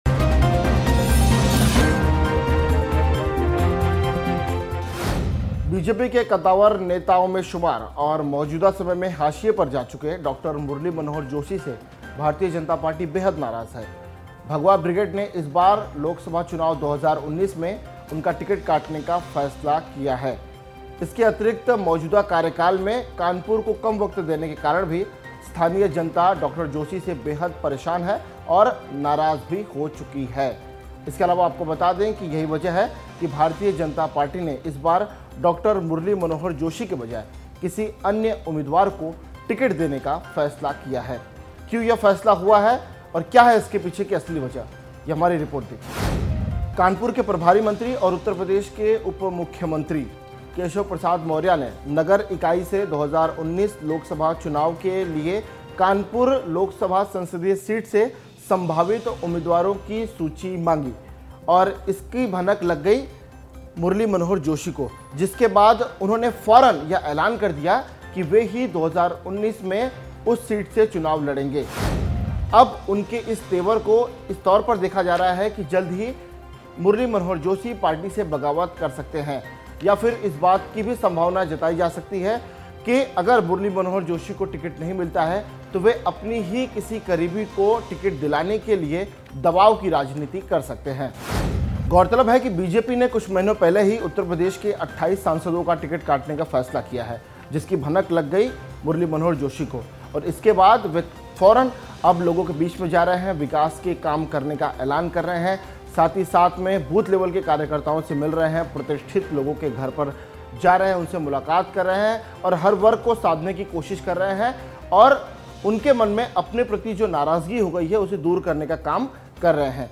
न्यूज़ रिपोर्ट - News Report Hindi / मुरली मनोहर जोशी भड़के, लोकसभा चुनाव का टिकट कटा तो खैर नहीं !